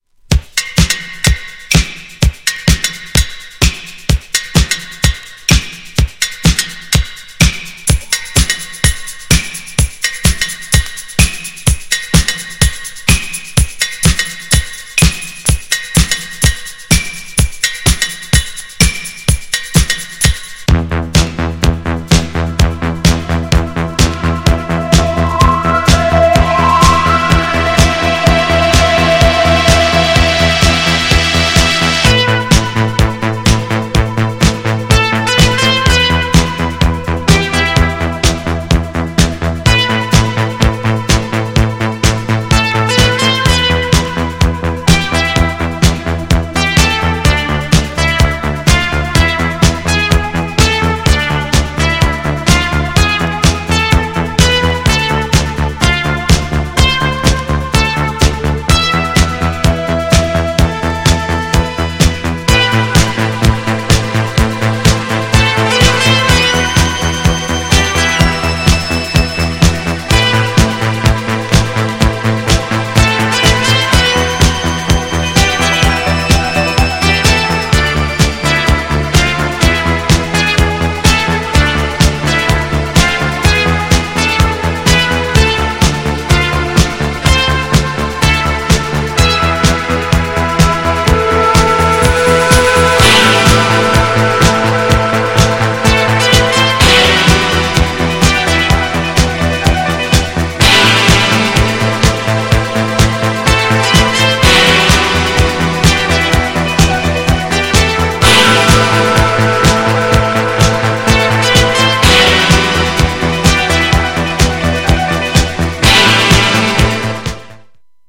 よりセンシティブでスケール感とイタロなムードが増したカナダ盤ONLYのNEW VERSION!!
GENRE Dance Classic
BPM 126〜130BPM
# INSTRUMENTAL # ITALO_DISCO
# エレクトロ # コズミック # シンセ # パーカッシブ # 妖艶